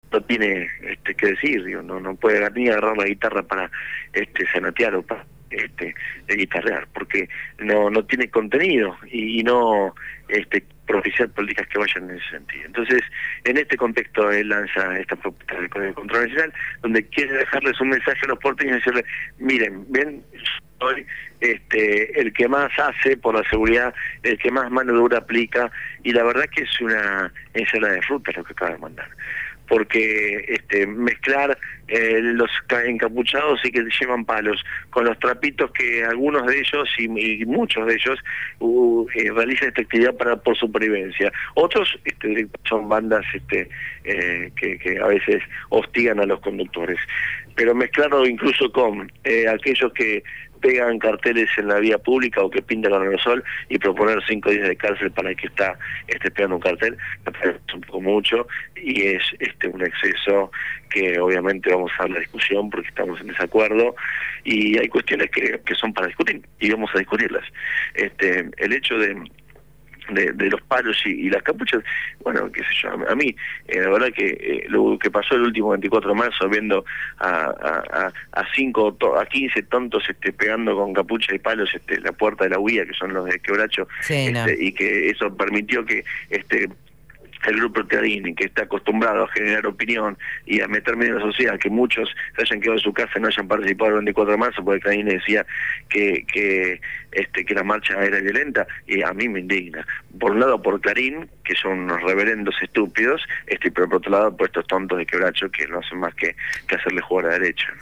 El Legislador porteño Juan Cabandié habló en «Punto de Partida» (Lunes a Jueves, de 8 a 10 de la mañana).